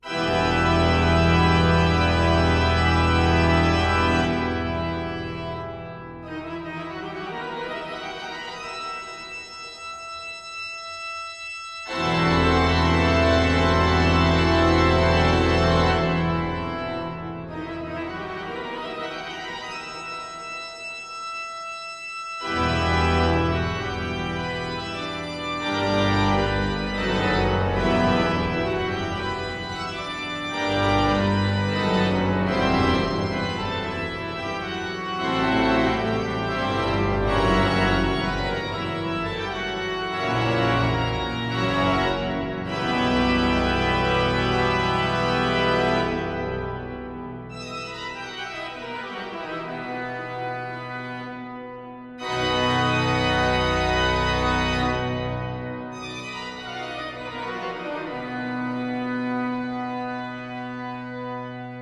Hören Sie sich einige Stücke an, gesungen von der Kantorei und dem JuLifa-Chor (Junge Lieder für alle) der Pfarrei Herz Jesu oder gespielt an der Schuke-Orgel der Stadtpfarrkirche!